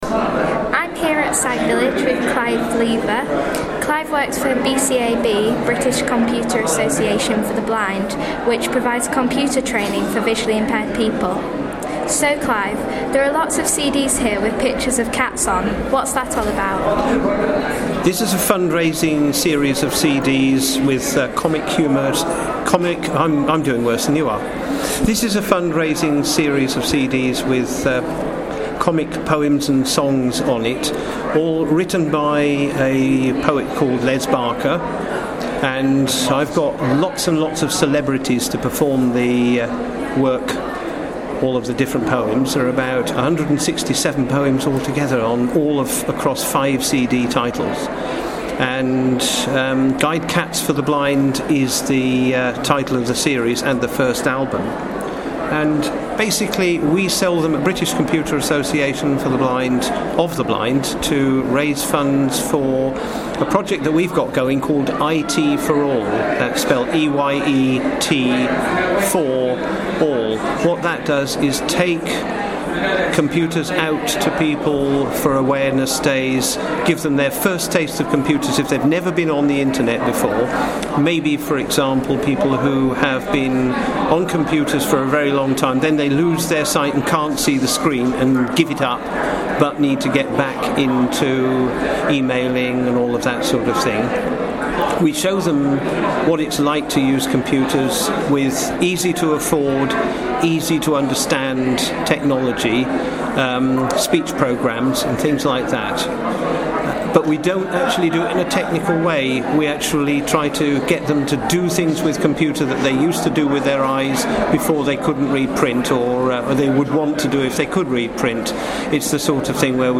'Guide Cats for The Blind' (Interview at sight village 2011)